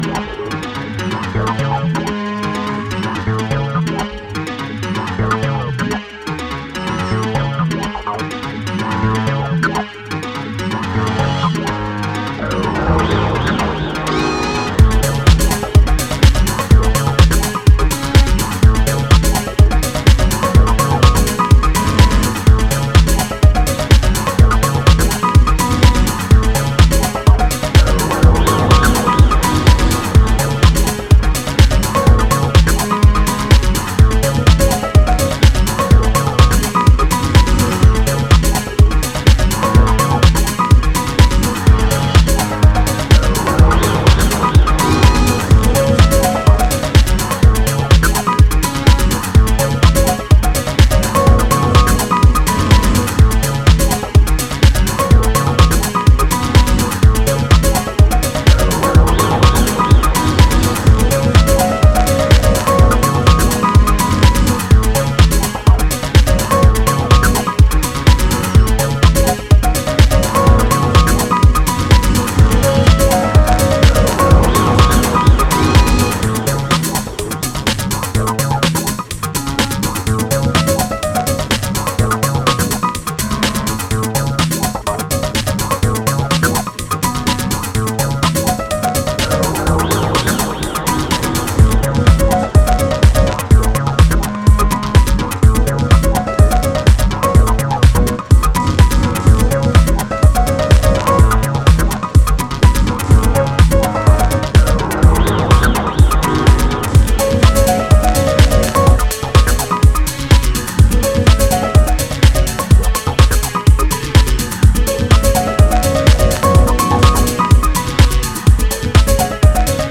deep, funky and sophisticated style of house music